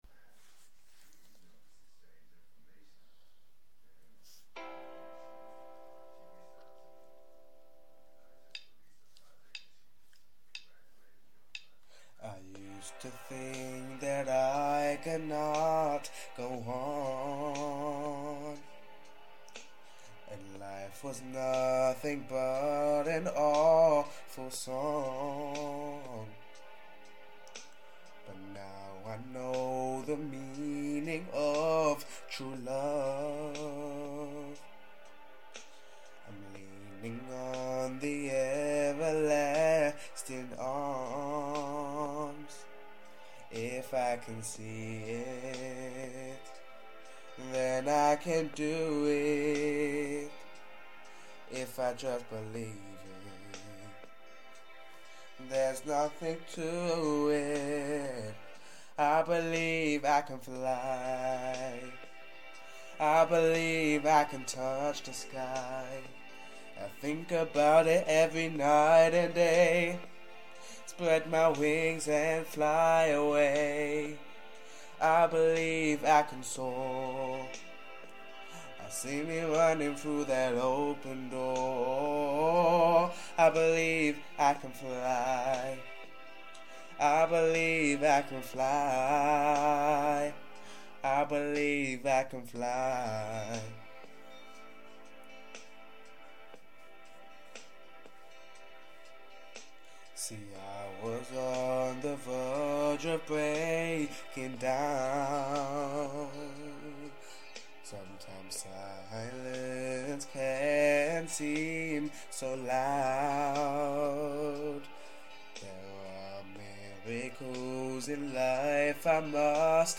Live Show 2